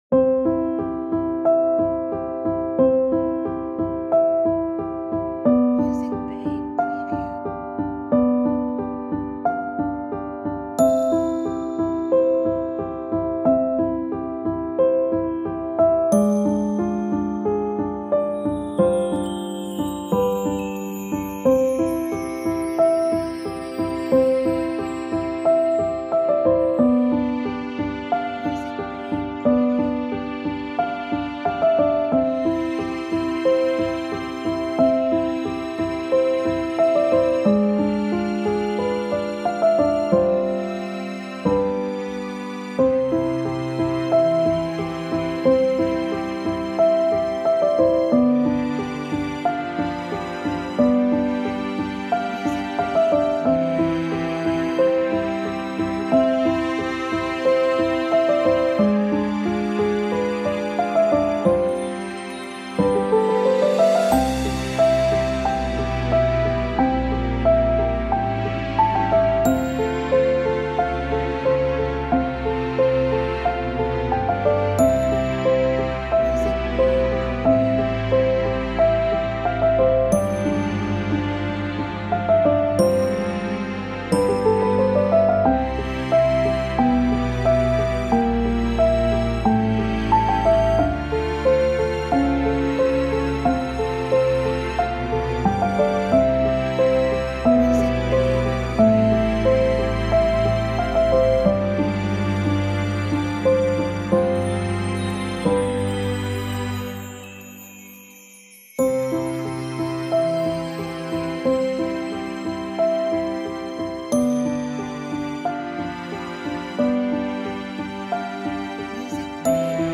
Royalty free music